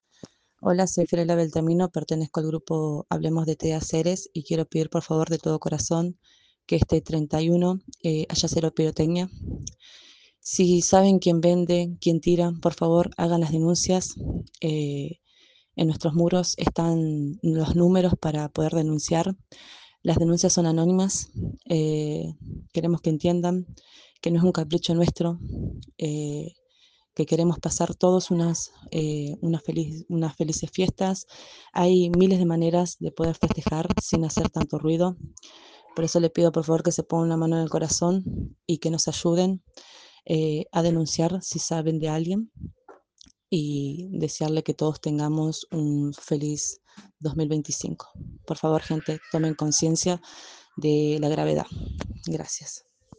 Escucha los audios de madres ceresinas de chicos con autismo….